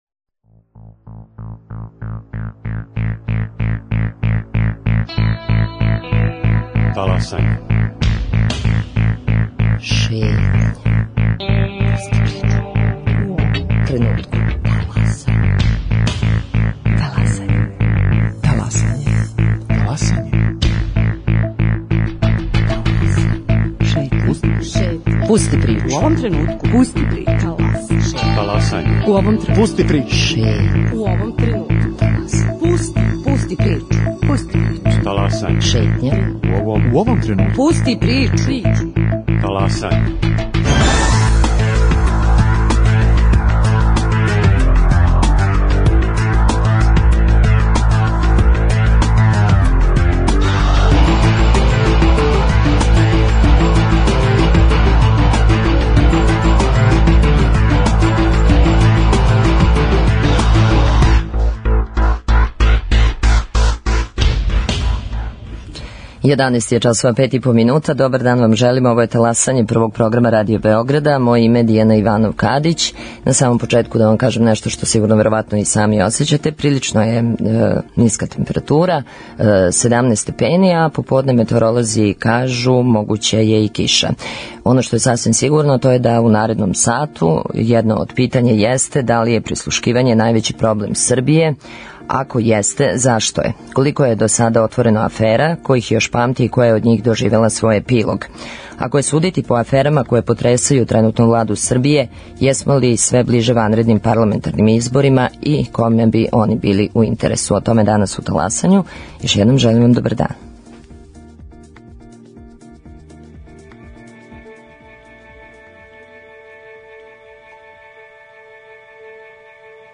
Гости: Драган Шутановац - бивши министар одбране и посланик ДС